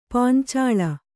♪ pāncāḷa